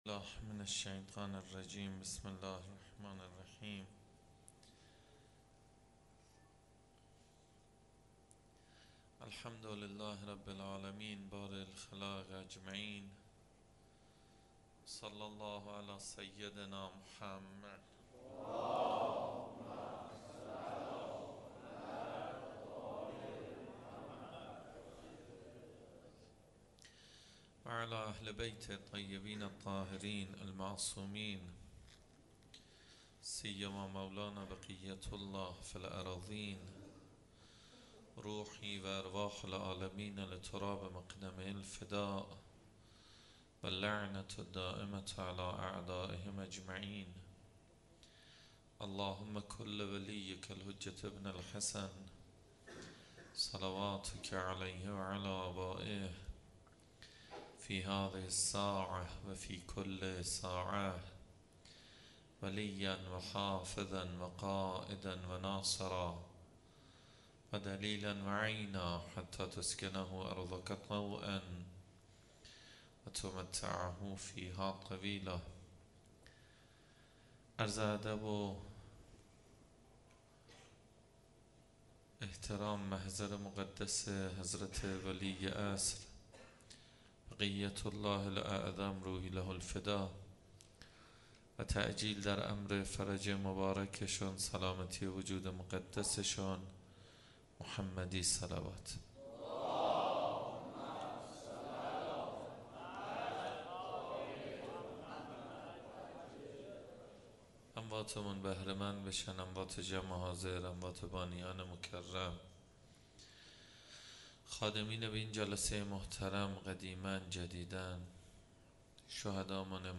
مراسم هفتگی 25.10.1393
حسینیه حضرت زینب(س)